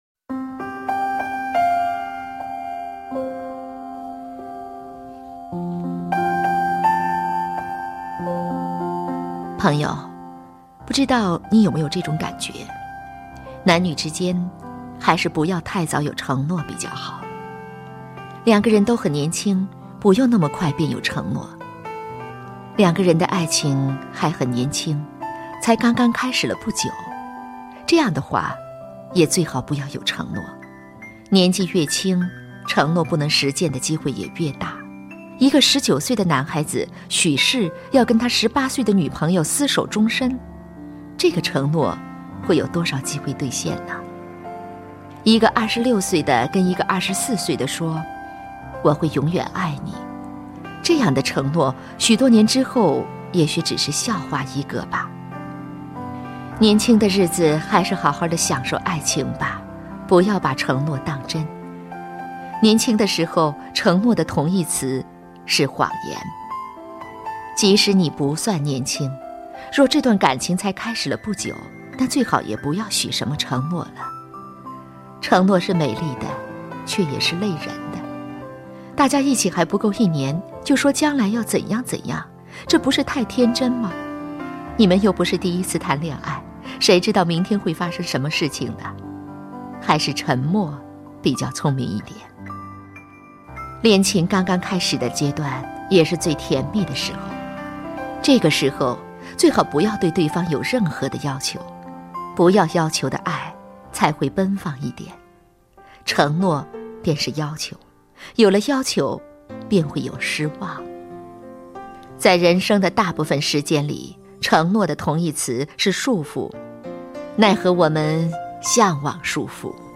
首页 视听 经典朗诵欣赏 张小娴：爱，从来就是一件千回百转的事